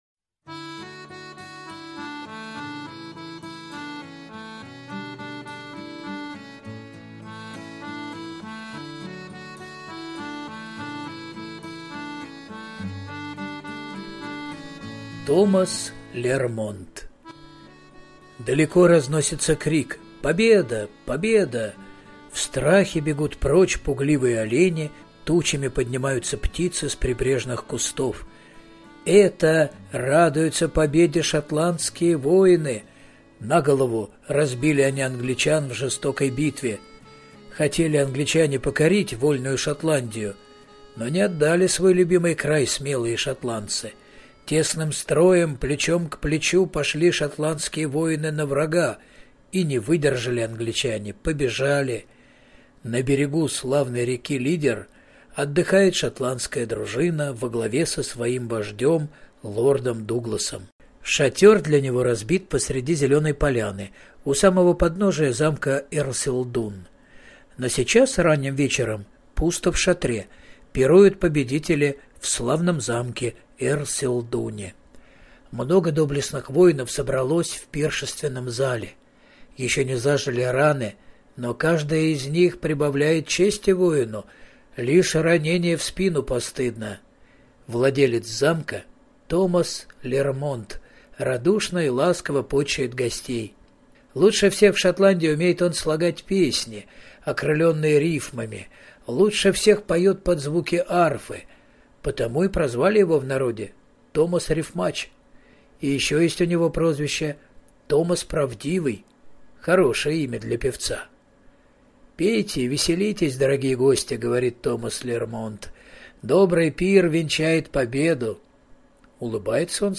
Томас Лермонт - аудио легенда - слушать онлайн